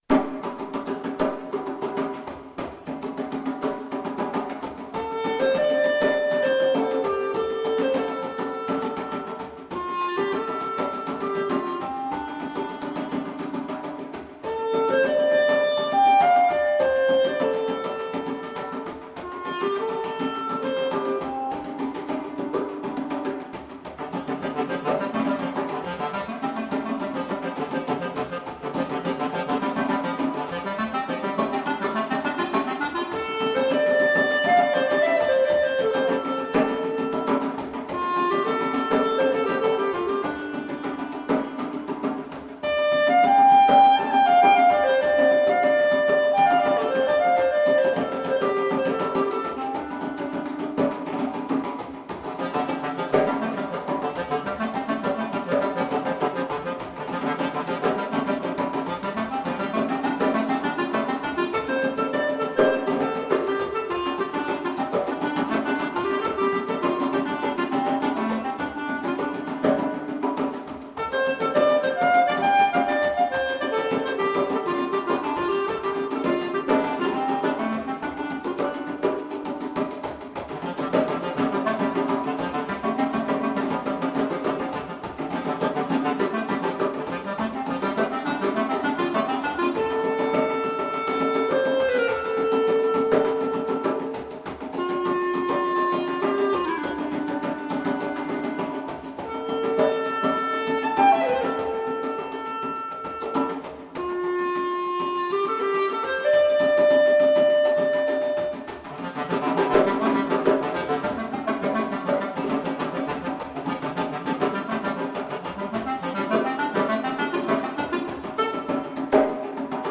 for clarinet and djembe | per clarinetto e djembe